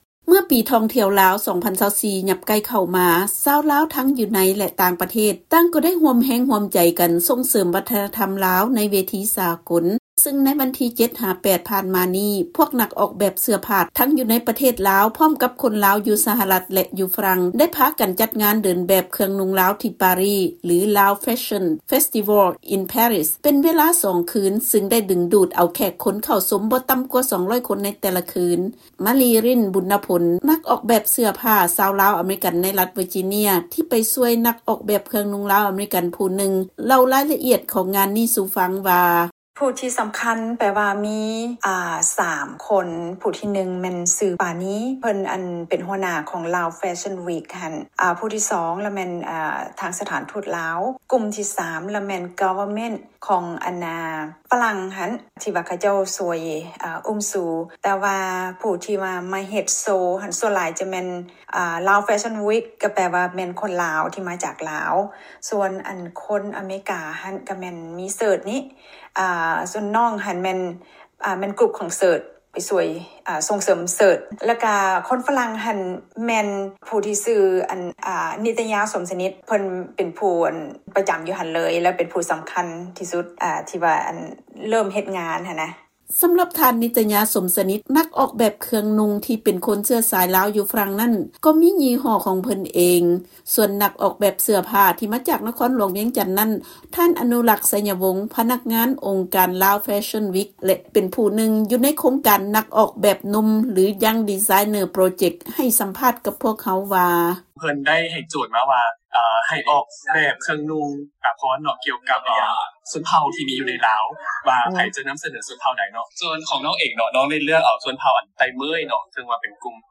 ຟັງລາຍງານ ນັກອອກແບບເສື້ອຜ້າລາວທັງຢູ່ໃນ ແລະຕ່າງປະເທດ ຮ່ວມກັນຈັດງານເດີນແບບເຄື່ອງນຸ່ງລາວ ຢູ່ທີ່ປາຣີ